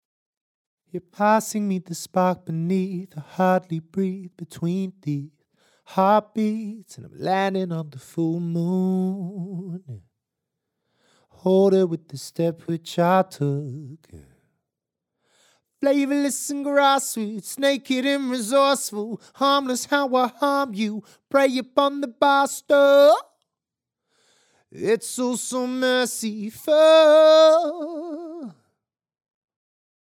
Condenser, externally polarized
Cardioid
ray_male-vox_mix-18lufs.mp3